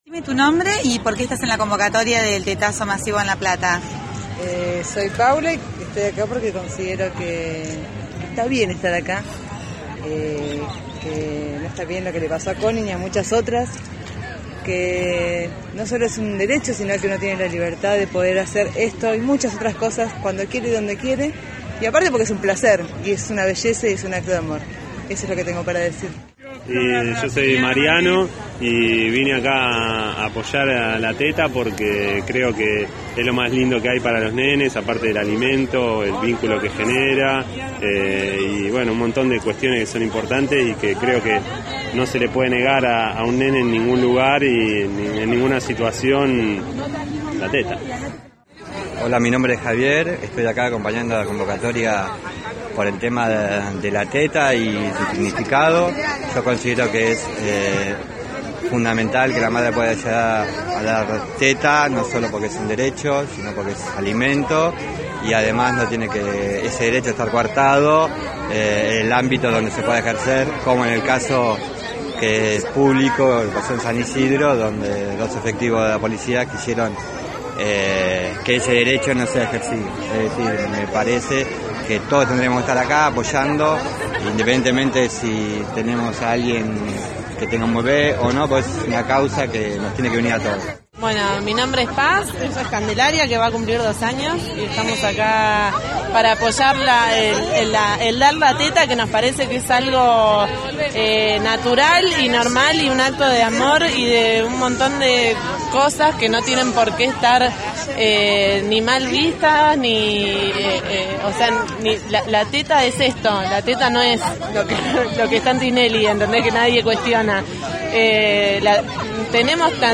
Testimonios de mujeres en la plaza:
Compilado-mujeres-.mp3